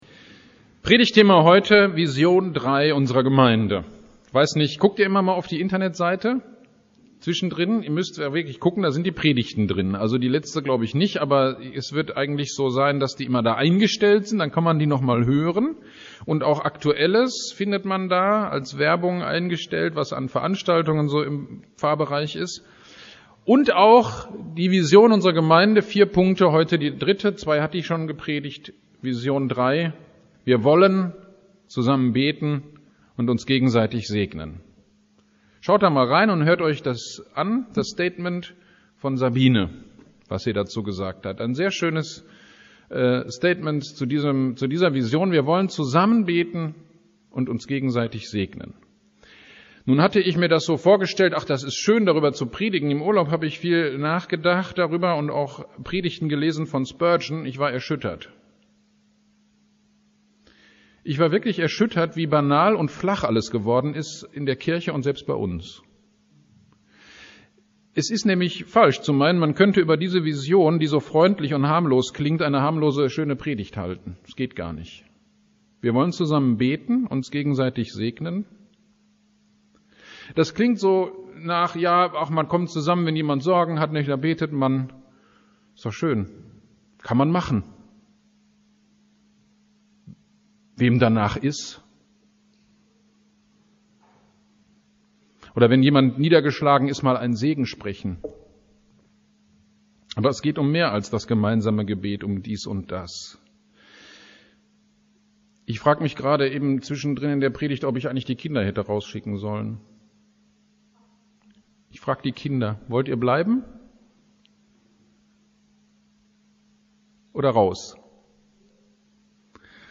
Predigt über 2. Korinther 10,4 zum Thema Gebet